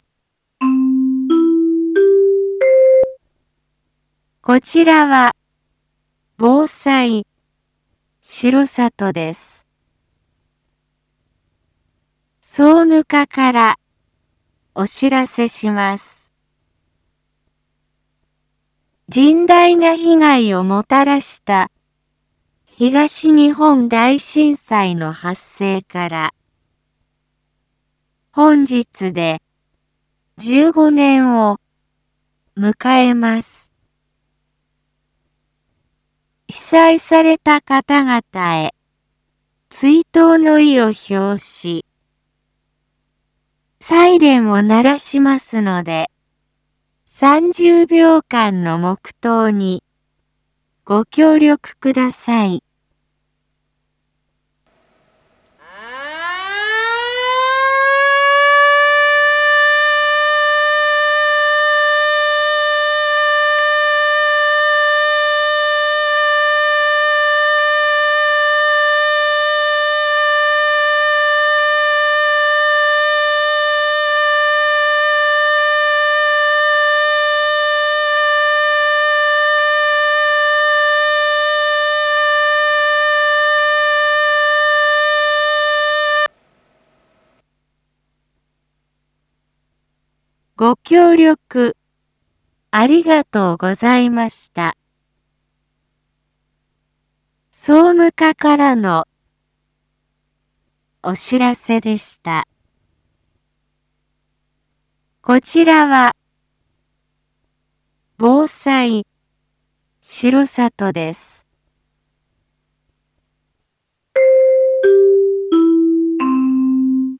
一般放送情報
BO-SAI navi Back Home 一般放送情報 音声放送 再生 一般放送情報 登録日時：2026-03-11 14:47:08 タイトル：東日本大震災追悼サイレン インフォメーション：こちらは、防災しろさとです。
被災された方々へ、追悼の意を表しサイレンを鳴らしますので、３０秒間の黙祷にご協力ください。